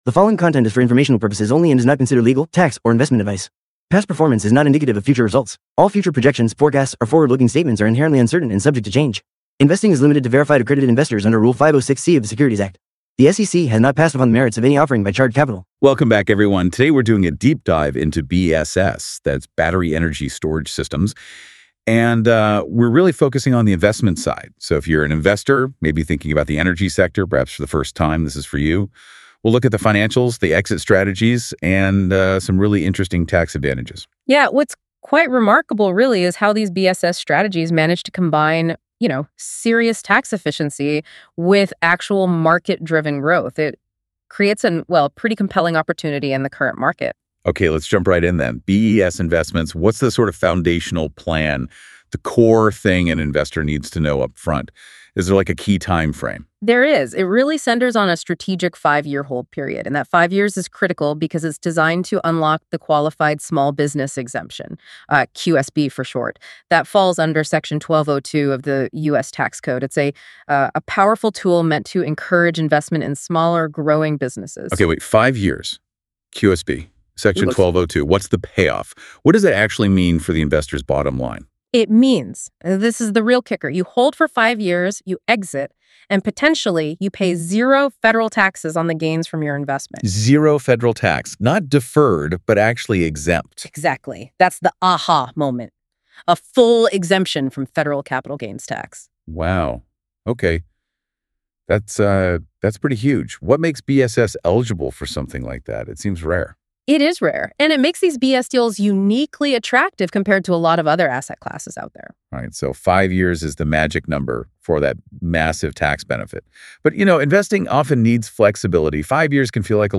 LISTEN ___________________________________________ Hear a detailed discussion on exit strategies and QSBS benefits in this podcast, and learn how they can enhance investor outcomes.